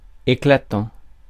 Ääntäminen
Ääntäminen France: IPA: [e.kla.tɑ̃]